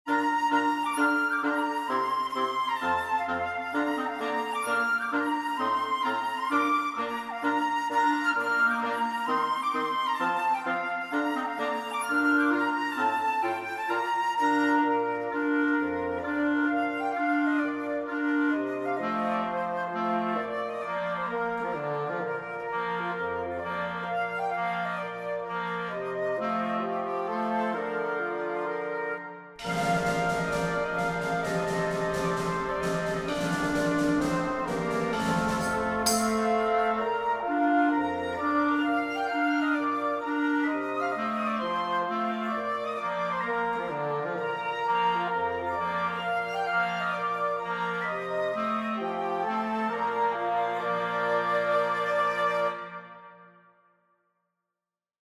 woodwinds
so I threw a woodwind quintet and percussion at it.